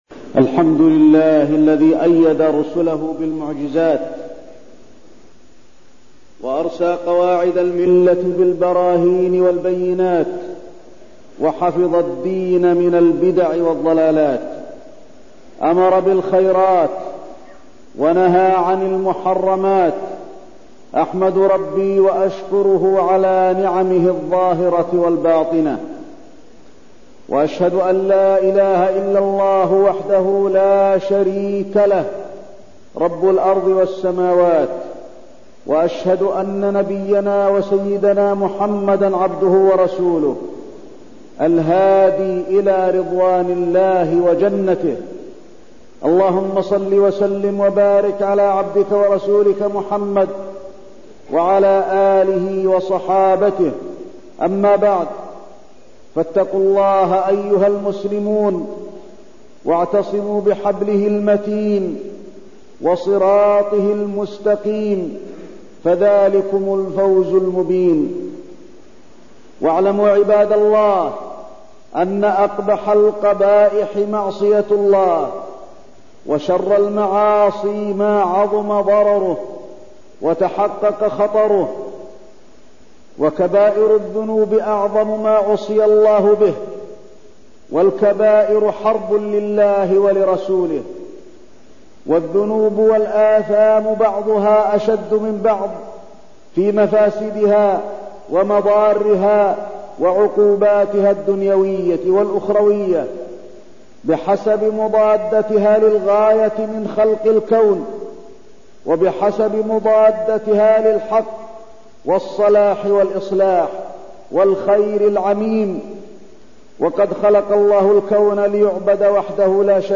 تاريخ النشر ٢٤ شوال ١٤١٣ هـ المكان: المسجد النبوي الشيخ: فضيلة الشيخ د. علي بن عبدالرحمن الحذيفي فضيلة الشيخ د. علي بن عبدالرحمن الحذيفي السحر وأضراره